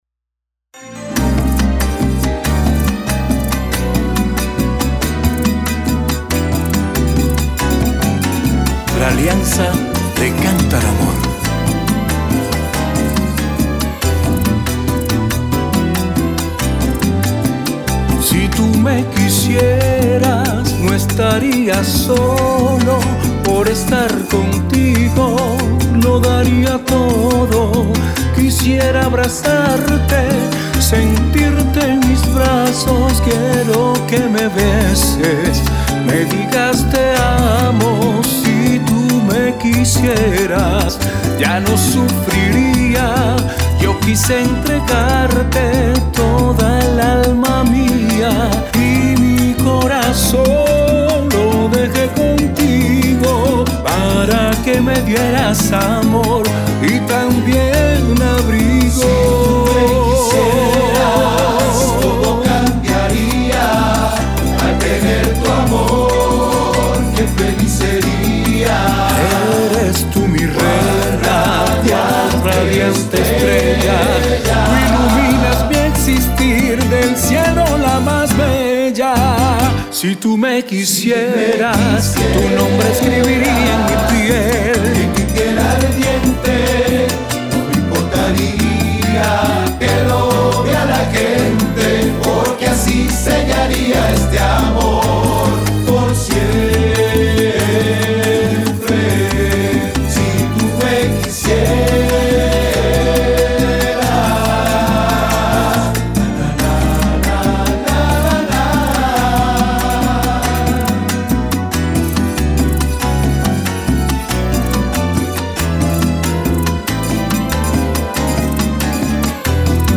la agrupación gaitera más emblemática de Barquisimeto